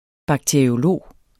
Udtale [ bɑgteɐ̯ioˈloˀ ]